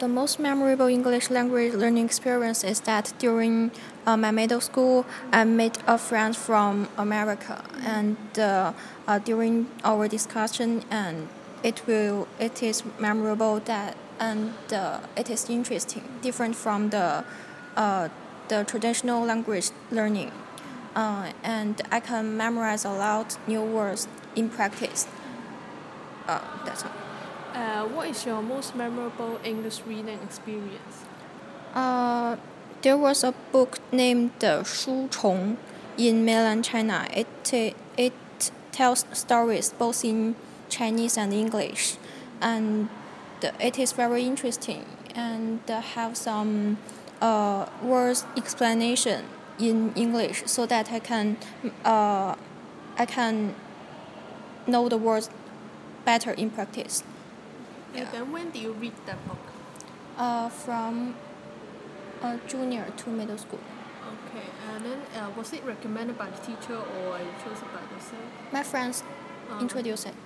Subcategory: Fiction, Reading, Speech